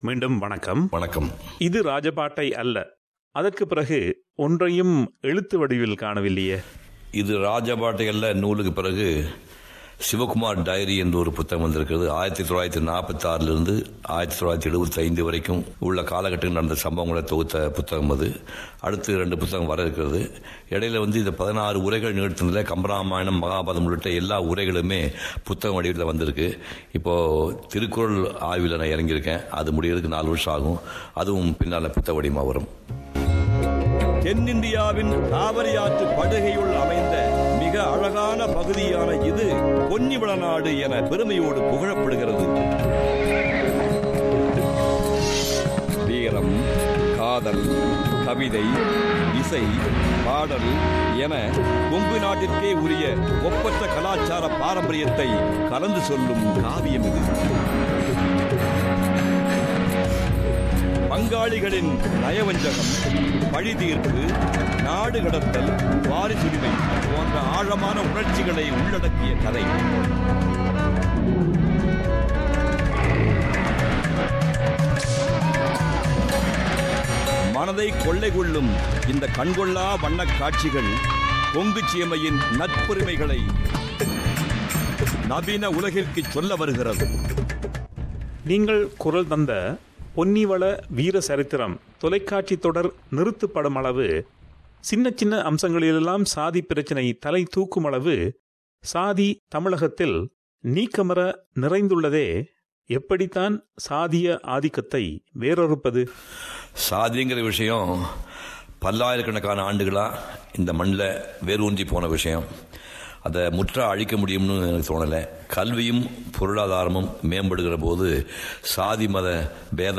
Painter, Actor, and Orator Sivakumar is a multi-facet artist.